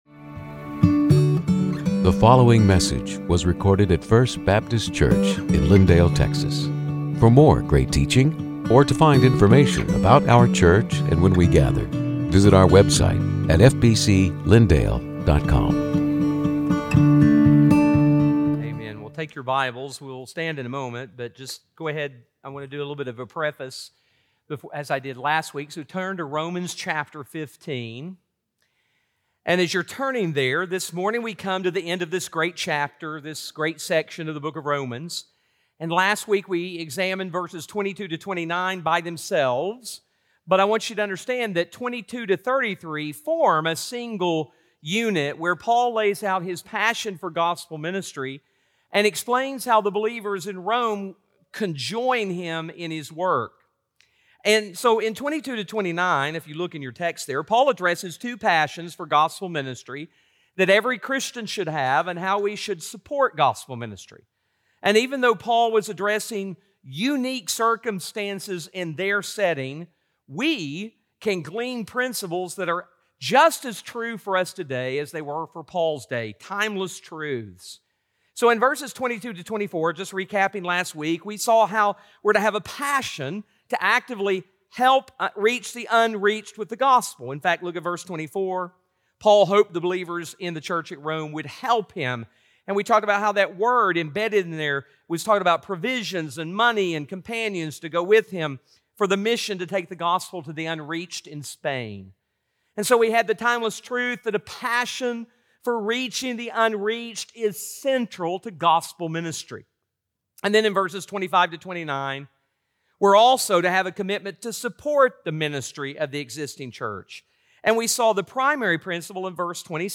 Sermons › Romans 15:30-33